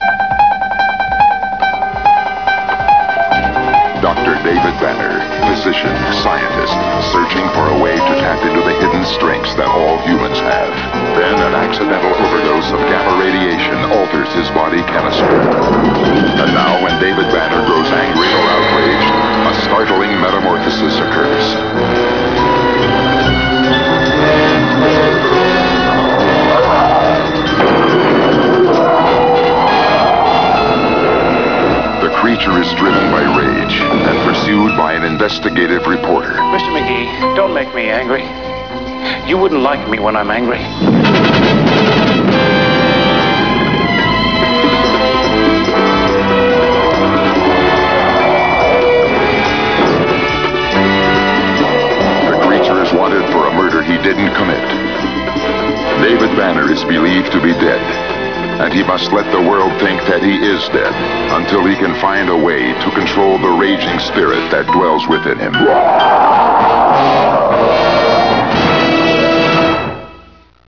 themesong & opening narration